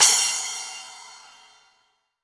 2SA CYMB.WAV